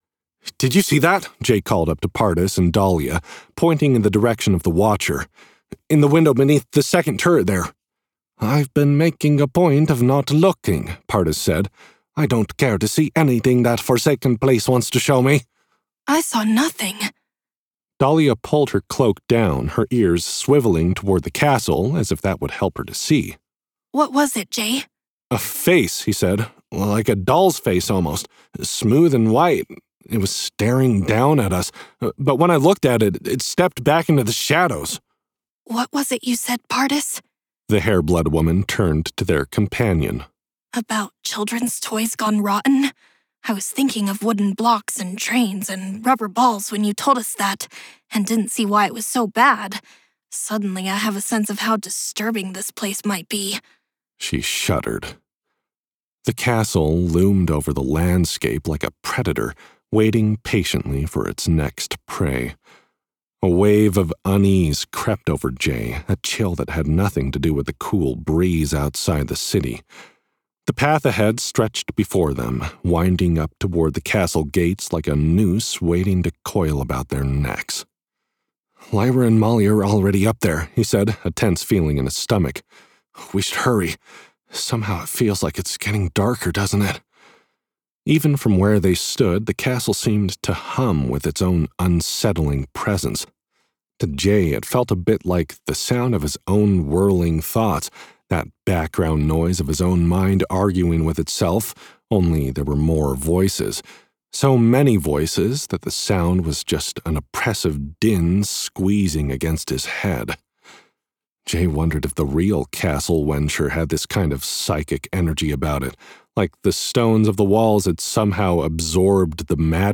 LitRPG: Chilling vibes from horror-themed castle level